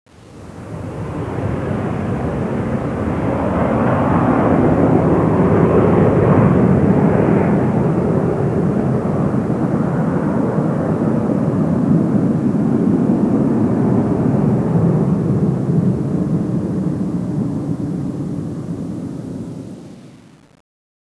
jetflyby1.wav